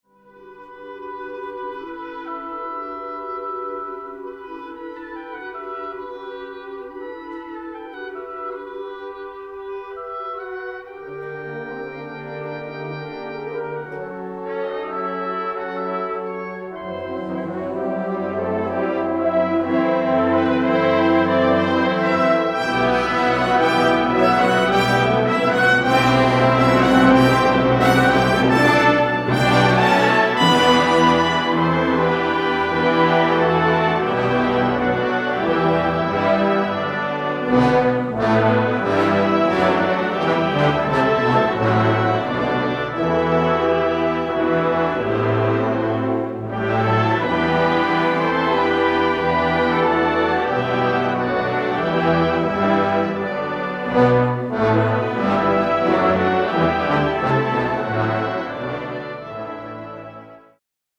This is a major work for Concert Band or Symphonic Wind Band